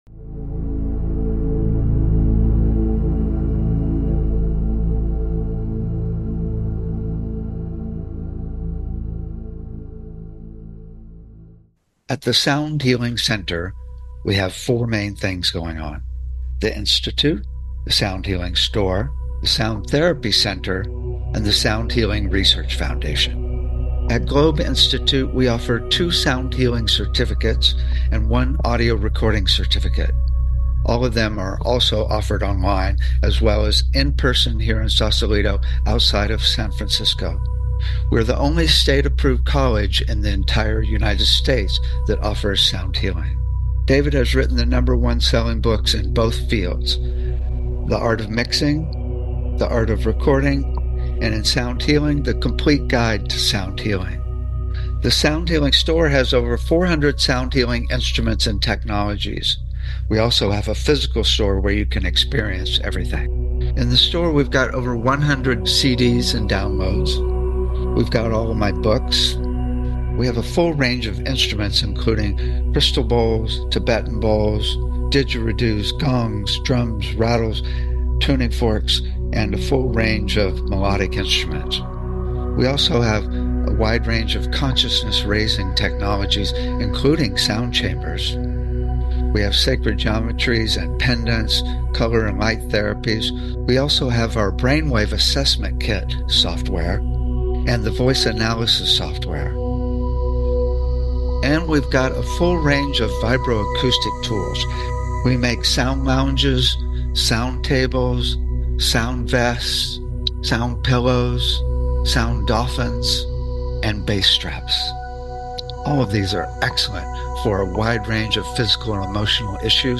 Talk Show Episode, Audio Podcast, Sound Healing and Channeling Archangels on , show guests , about Channeling Archangels,Sound Healing, categorized as Education,Energy Healing,Sound Healing,Love & Relationships,Emotional Health and Freedom,Mental Health,Science,Self Help,Spiritual